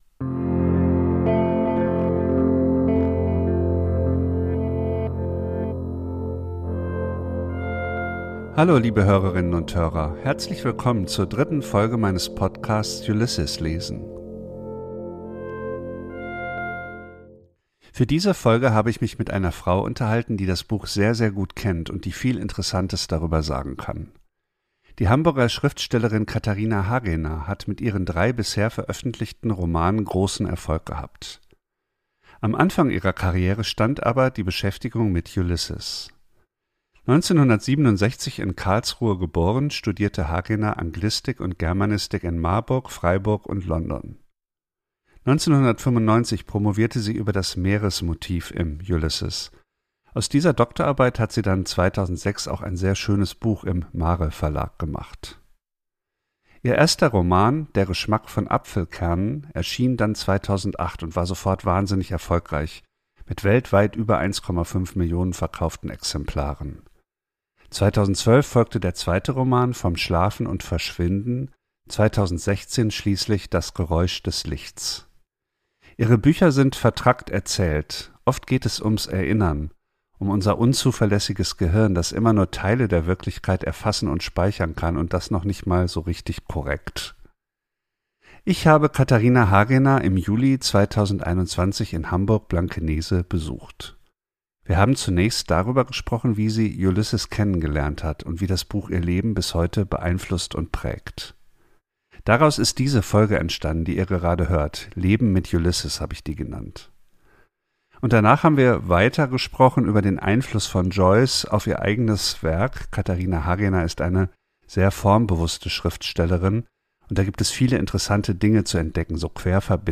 In dieser Podcast-Episode erzählt sie von ihrem Leben mit dem Buch, berichtet über wilde Streits in der Zürcher Joyce-Stiftung und erklärt, warum »Ulysses« für sie ein Werk des Scheiterns ist. Teil 2 des Gesprächs folgt das im September: »Schreiben mit Ulysses«.